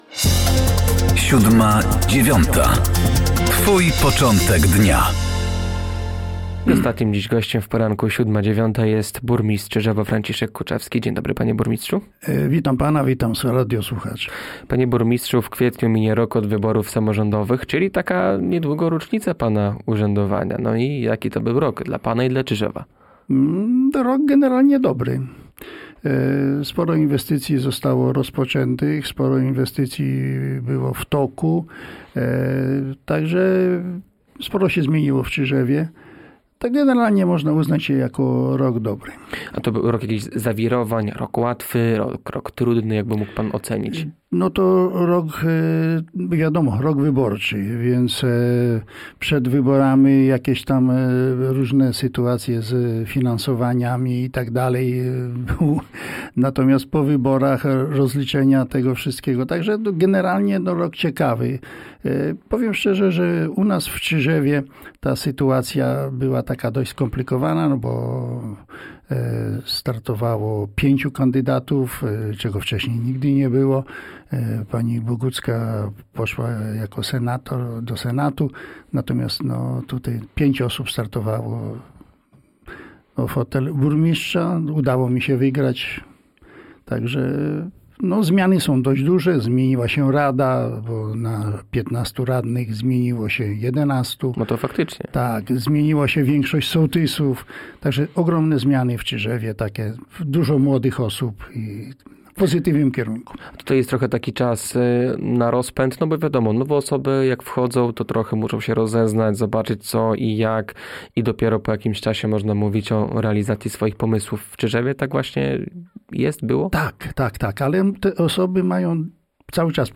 Gościem Poranka Siódma9 był burmistrz Czyżewa Franciszek Kuczewski.